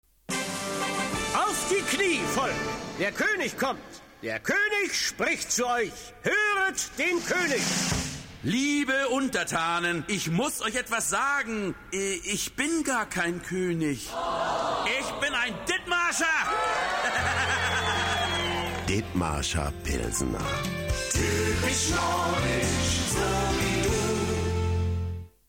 ditmarscher_werbung1.mp3